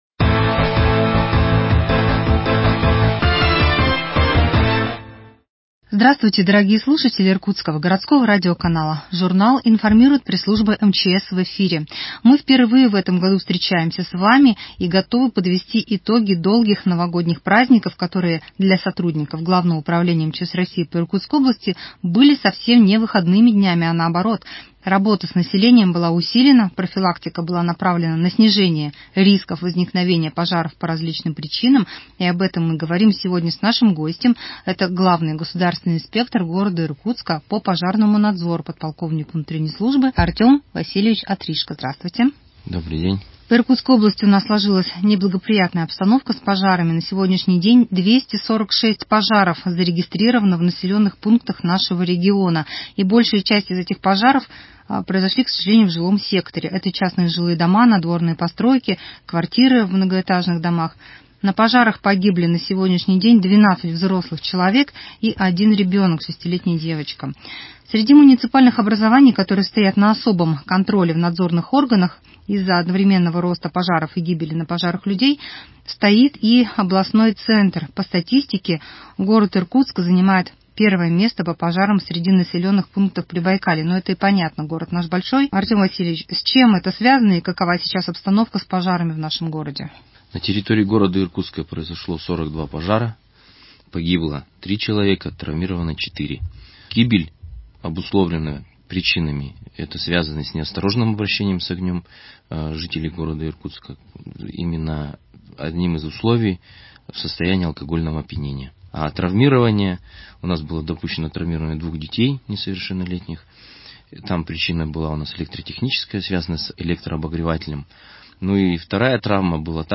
Еженедельная пятничная рубрика ГУ МЧС России по Иркутской области «Радиожурнал МЧС».